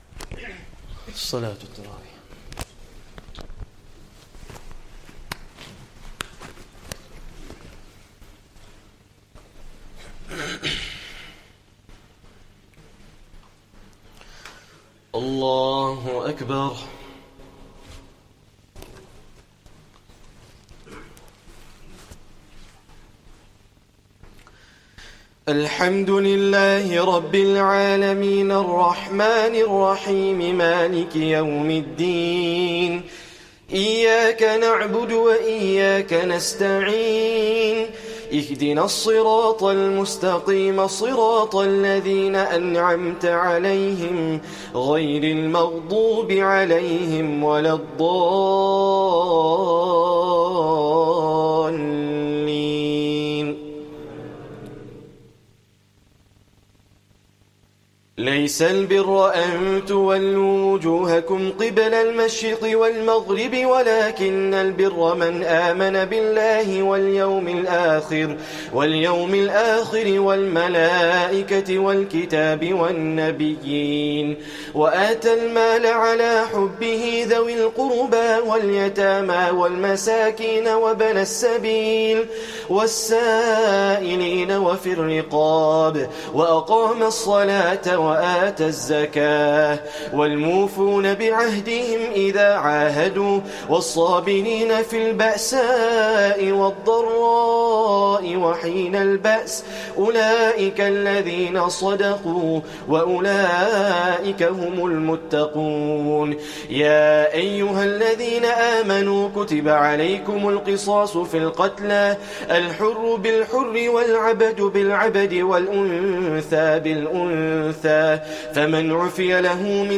Taraweeh Prayer 2nd Ramadhan